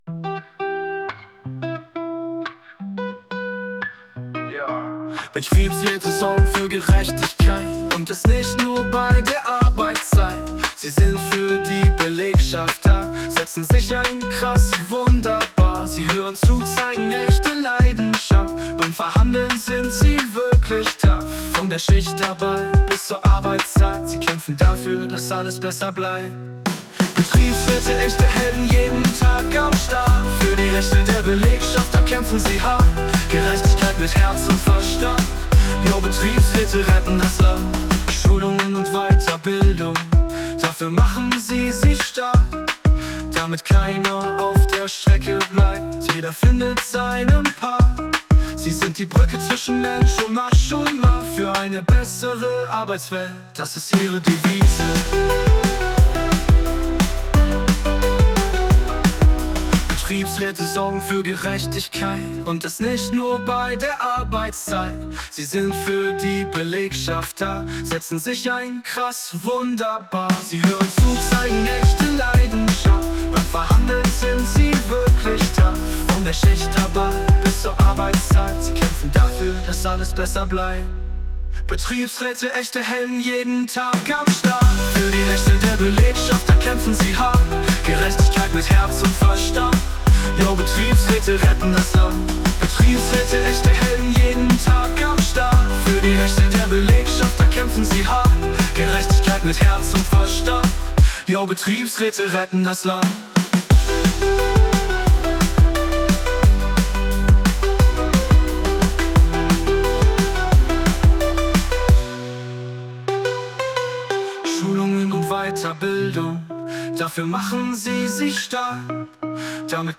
Betriebsrats-Songs (KI-generiert)
HipHop-Version
betriebsratssong---hiphop.mp3